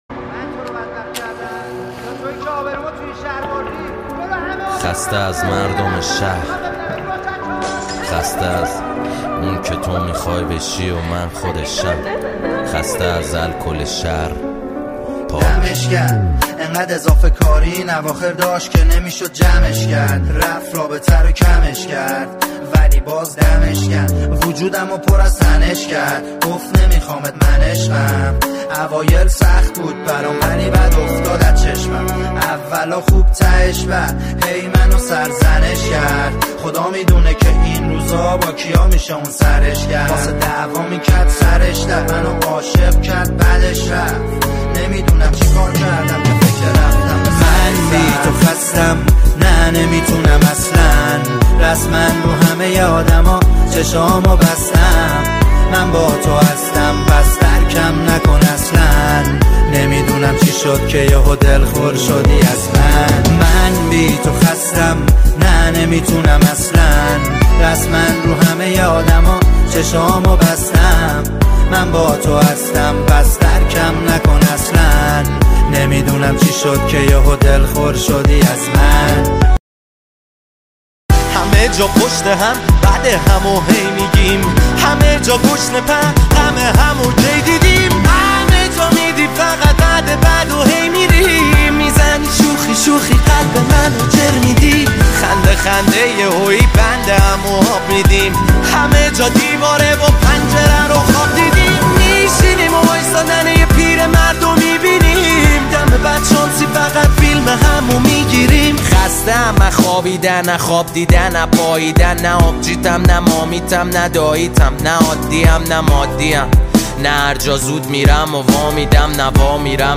• پاپ
دسته : پاپ